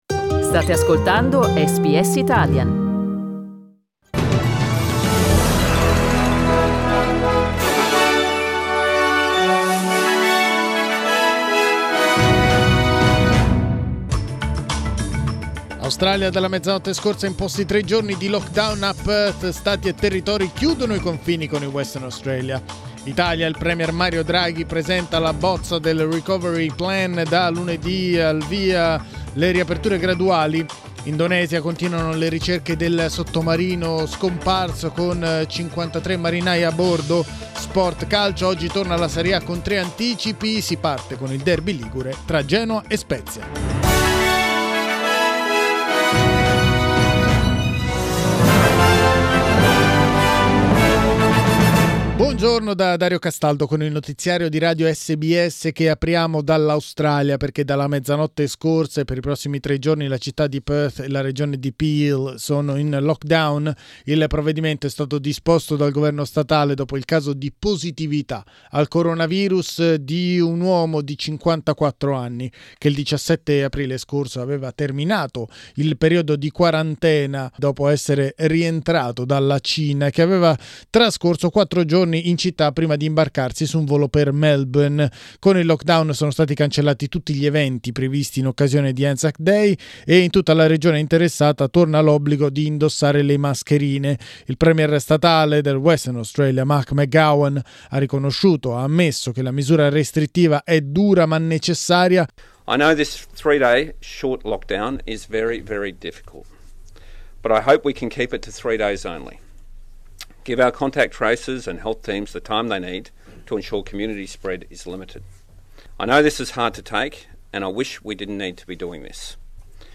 Il notiziario in italiano di SBS Italian.